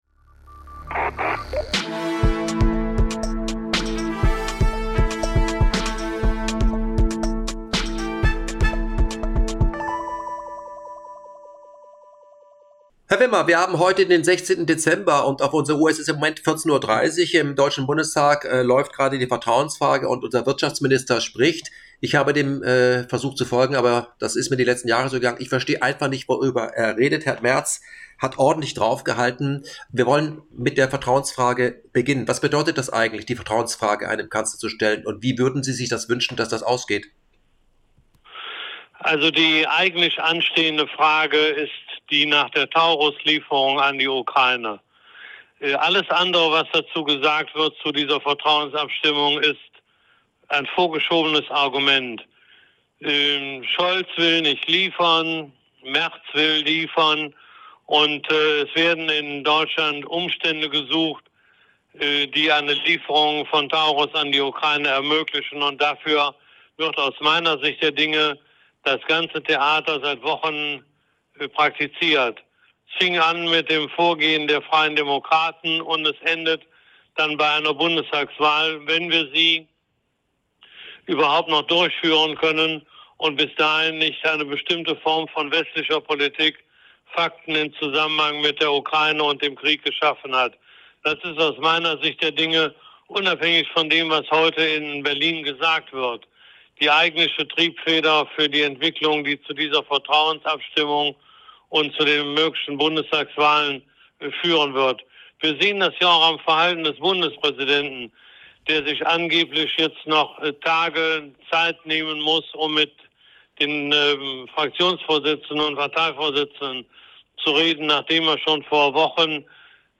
Interview mit Willy Wimmer – Der große Krieg kann kommen!